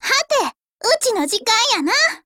技能台词
关西腔